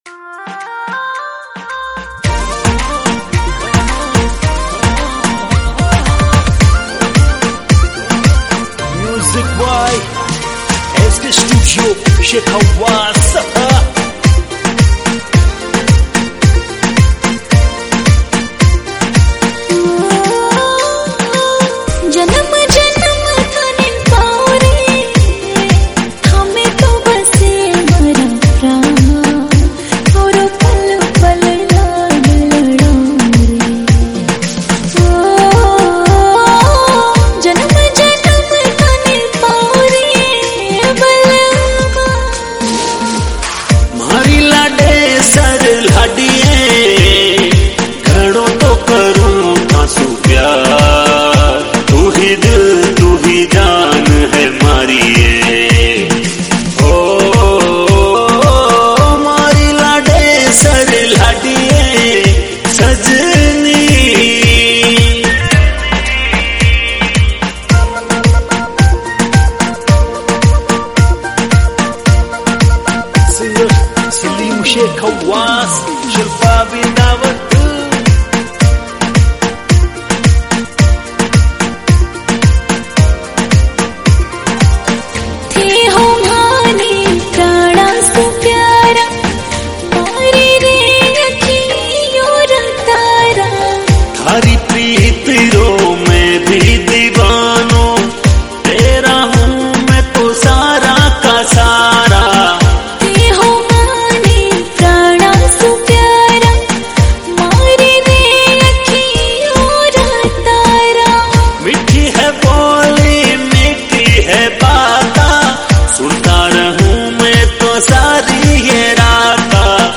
Category: Rajasthani